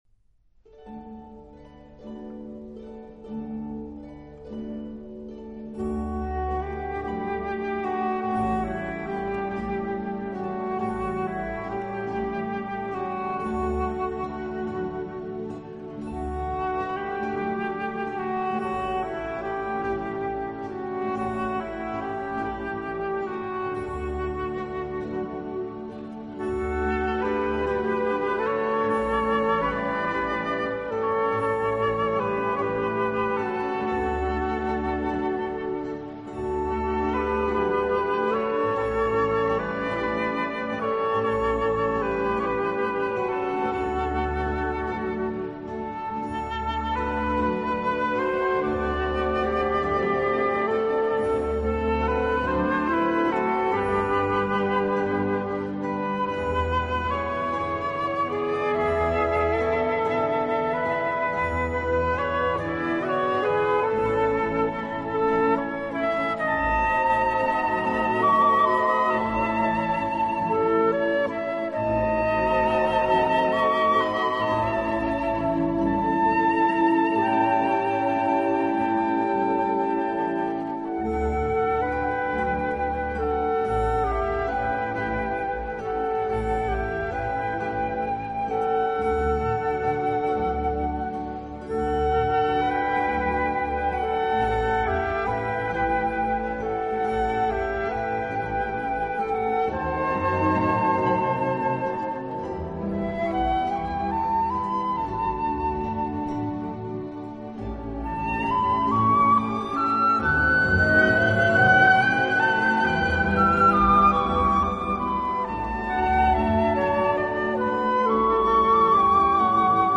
那自然如哨音，灵动如鸟啭的笛声，无距离地唱进你的
船歌